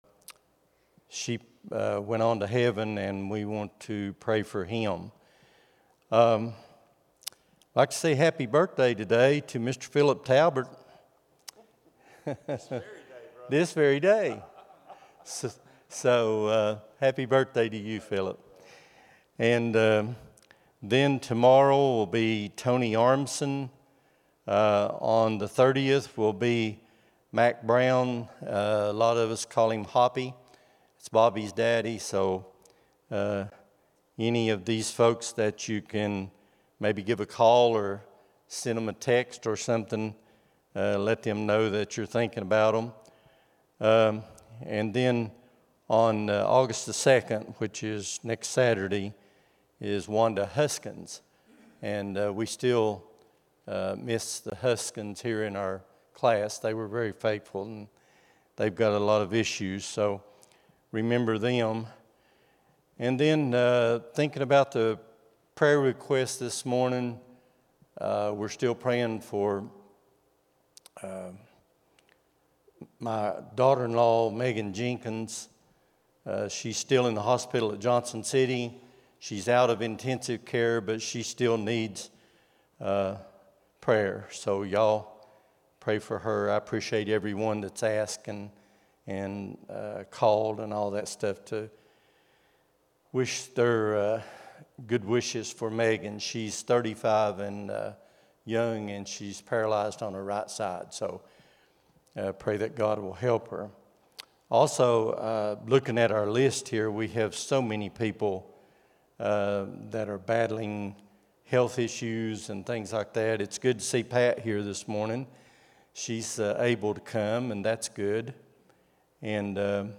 07-27-25 Sunday School | Buffalo Ridge Baptist Church